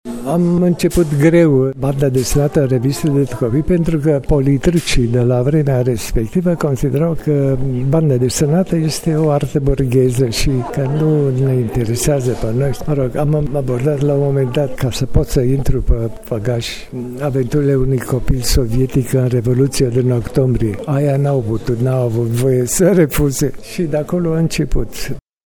Artistul Puiu Manu, maestru al benzii desenate în România: